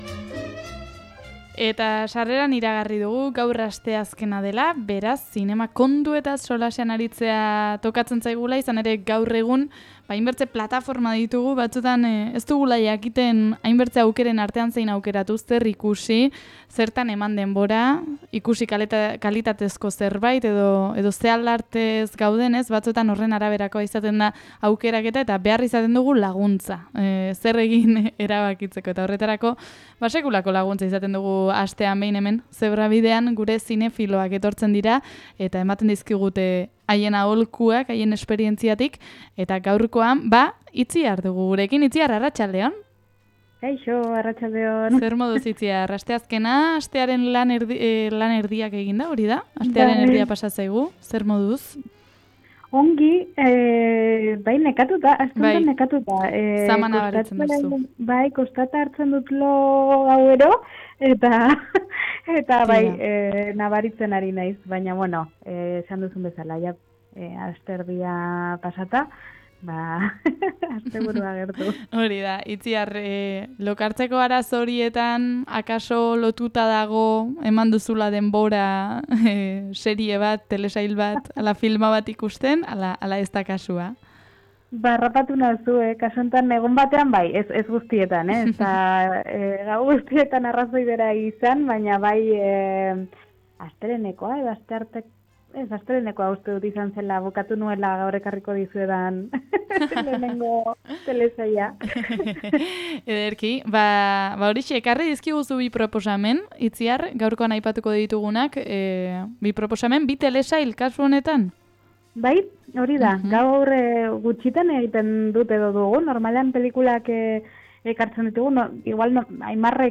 Asteazkenero gisan zinema eta telesailen inguruko tertulia izan dugu gurean.